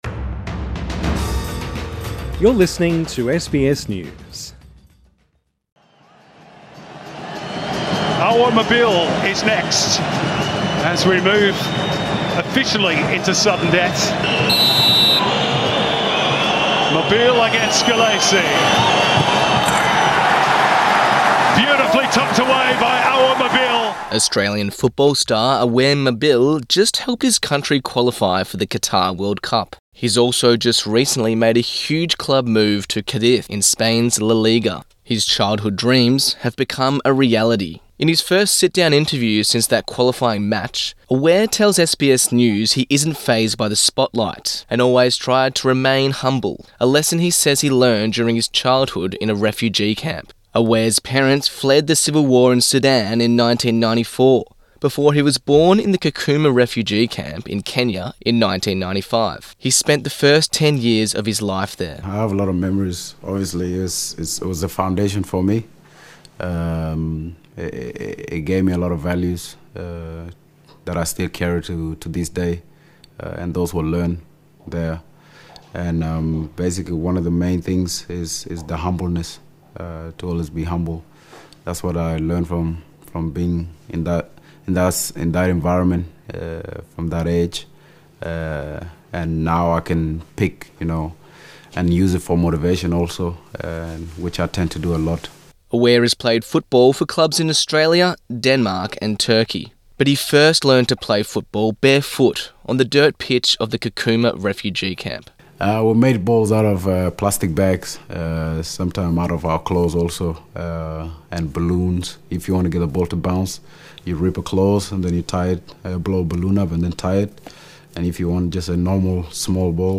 In an exclusive interview, the Socceroos star sits down with SBS News to describe his humble beginnings, and his journey to the top of Australian football.